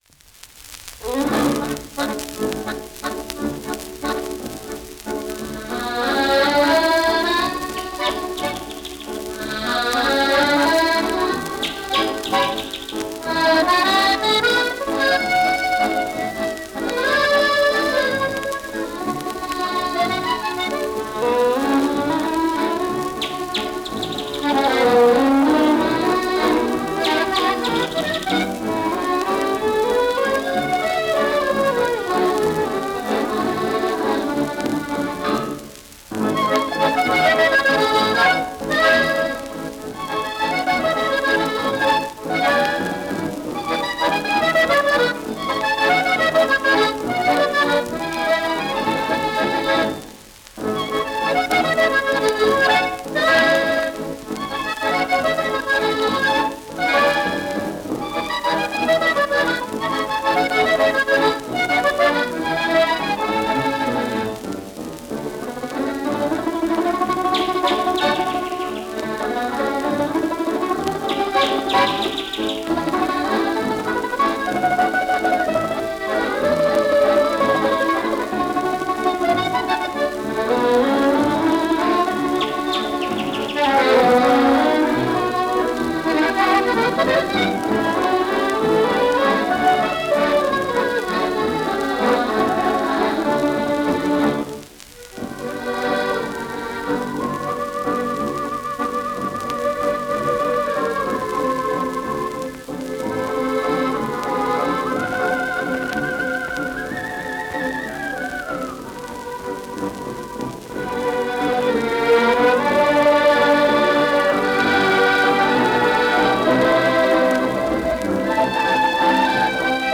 Судя по треску записано с пластинки.